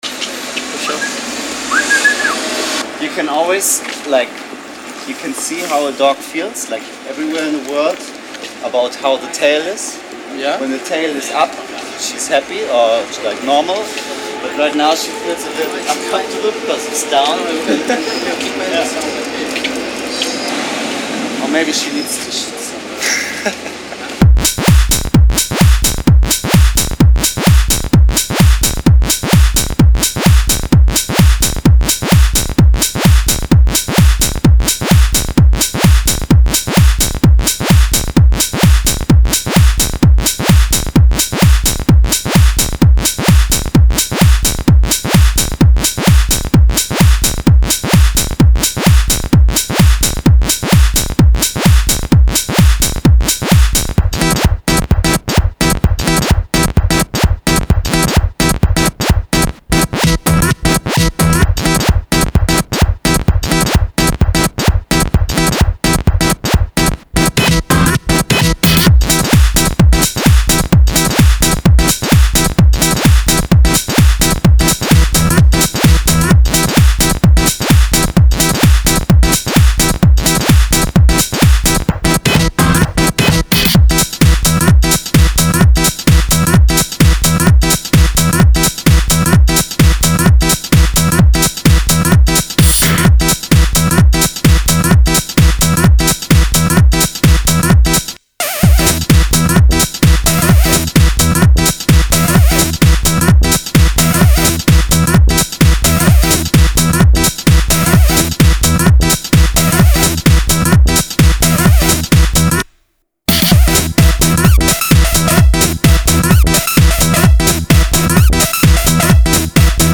Весёлое электро!!!!!!!!